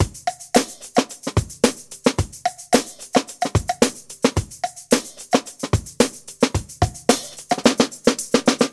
Unison Funk - 6 - 110bpm - Tops.wav